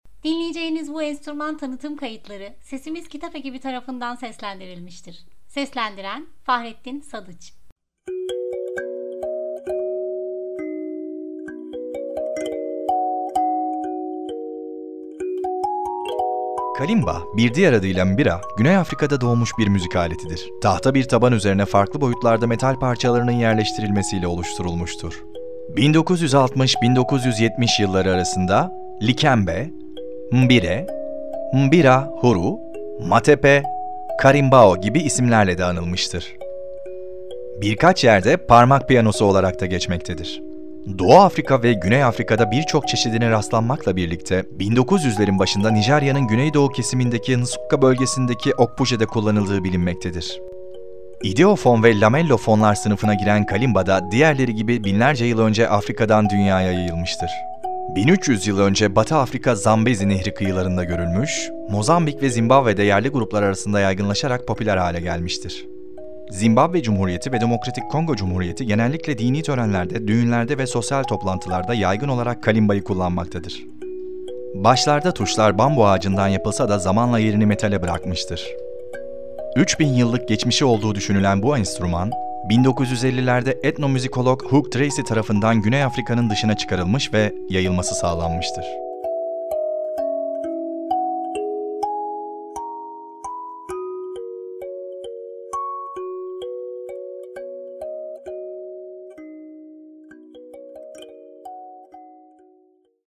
Kalimba
Müzik